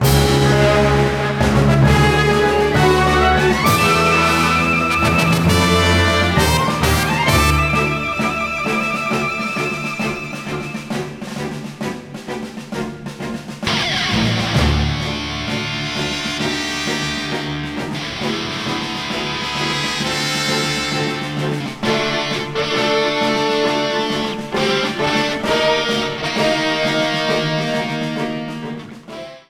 Game rip
Fair use music sample